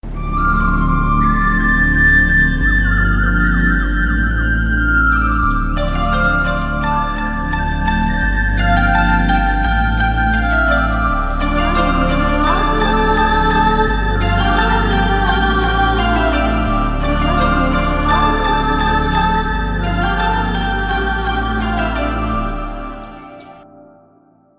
Enjoy the best background scores.